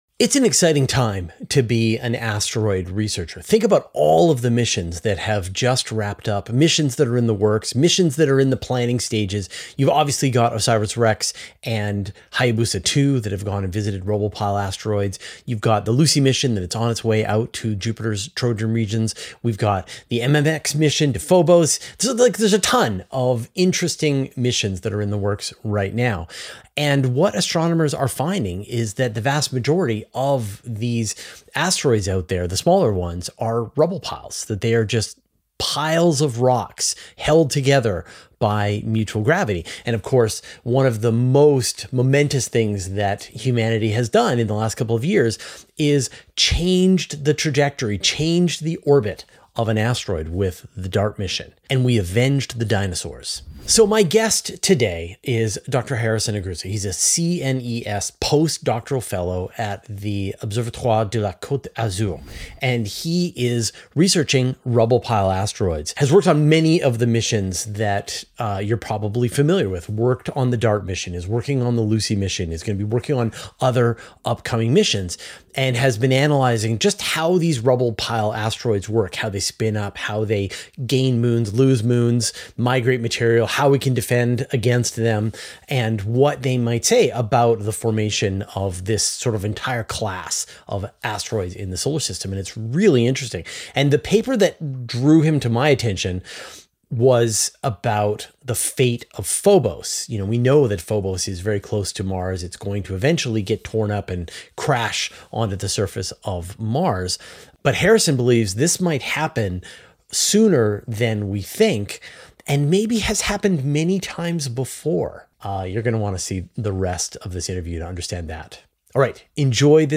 [Interview+] Phobos Might Already Be Destroyed and Reformed. Possibly Multiple Times